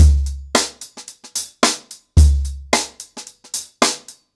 HarlemBrother-110BPM.11.wav